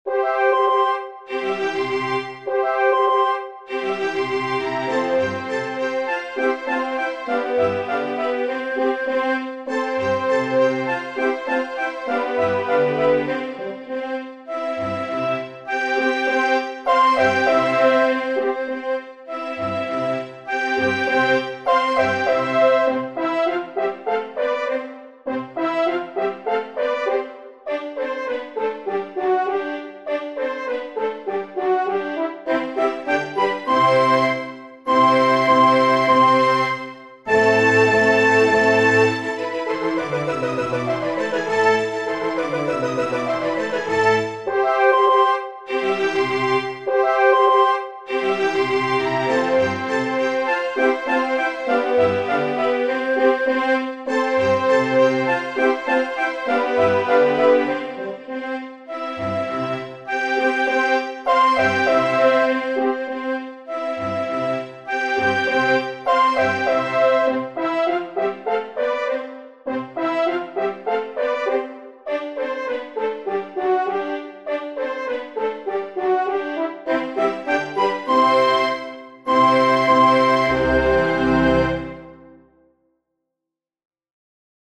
S33 - Orchestral and Large Ensemble - Young Composers Music Forum